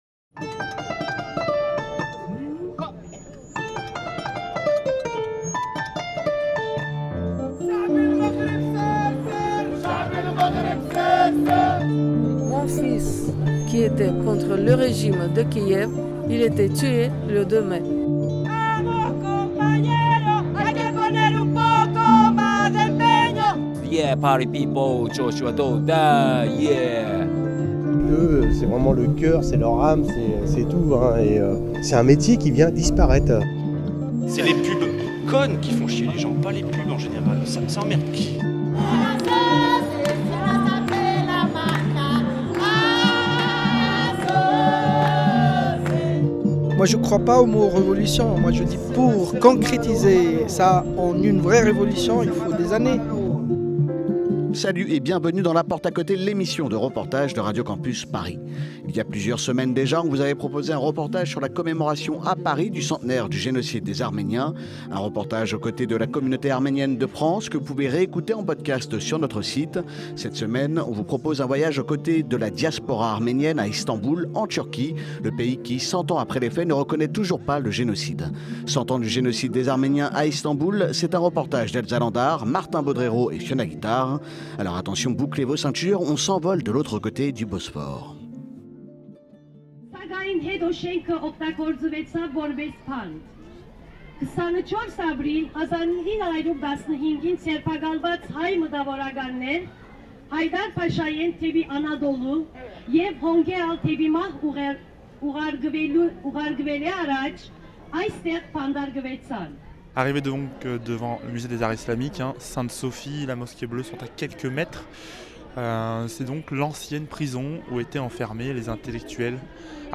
Cette semaine, on vous emmène pour un voyage avec la diaspora arménienne à Istanbul, en Turquie. Le pays qui, cent ans après, ne reconnaît toujours pas le génocide.